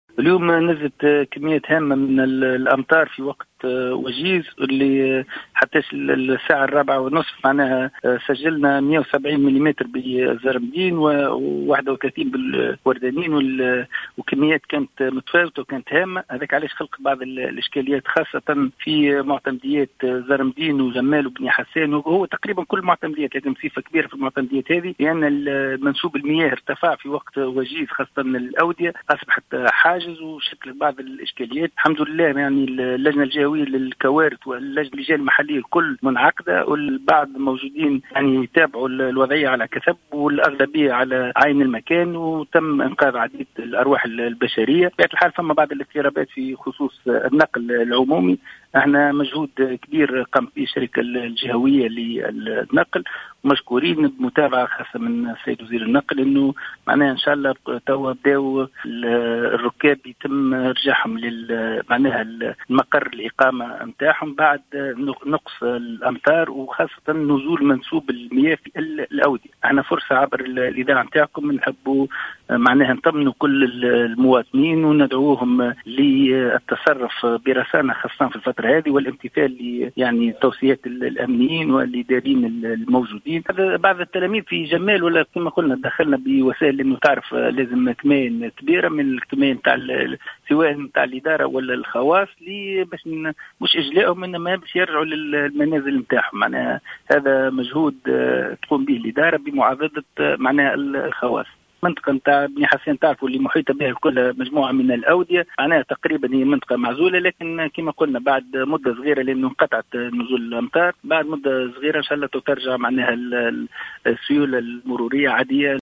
وأكد والي المنستير، عادل الخبثاني، في تصريح للجوهرة أف أم، أن اللجنة الجهوية لمجابهة الكوارث قد انعقدت لمتابعة الوضعية عن كثب حيث تم التدخل لإنقاذ عدد من المتضررين، فيما تحاول شركة النقل بالساحل تأمين السفرات في ظل النقص الكبير في بقية وسائل النقل.